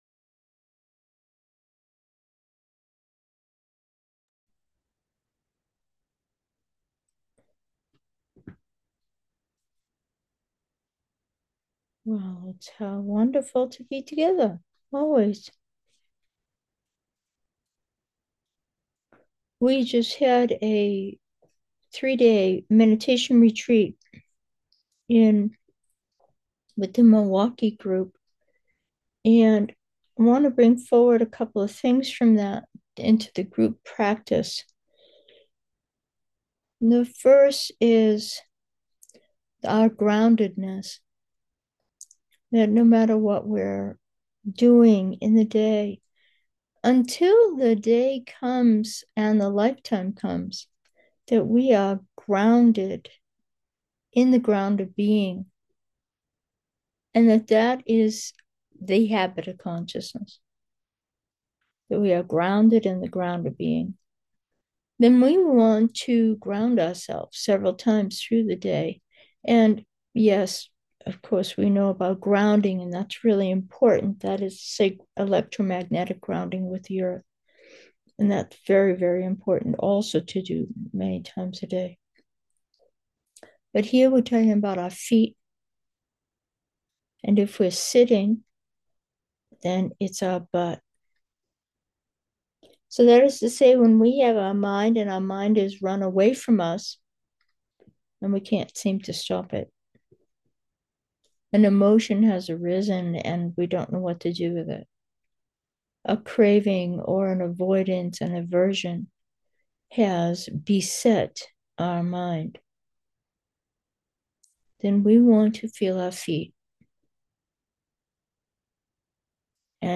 Meditation: three simple ones